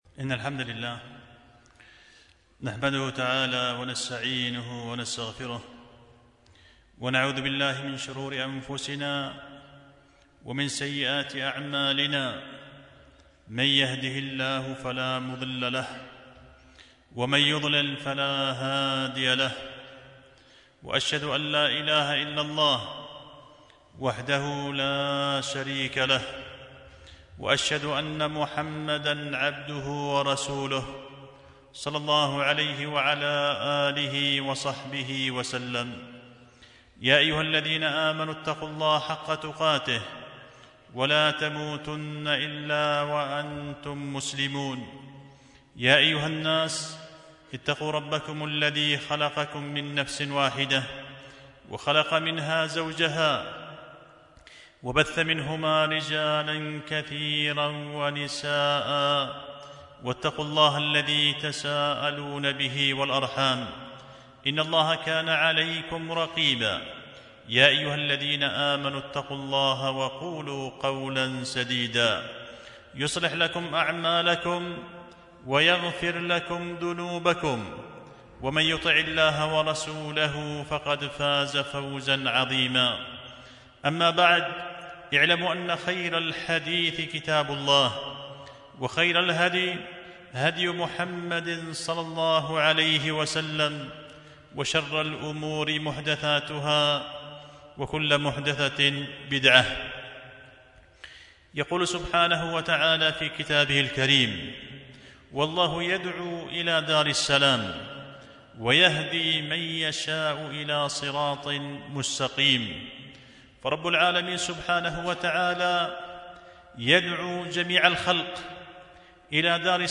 خطبة جمعة بعنوان فتح المنان في بيان أسباب بناء البيوت في الجنان